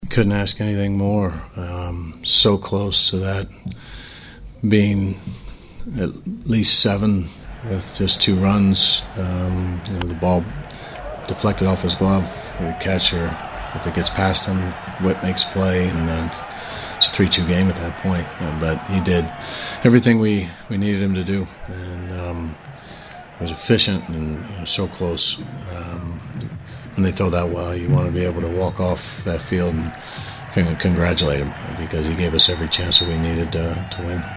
Manager Mike Matheny called it a good outing.
6-27-mike-matheny.mp3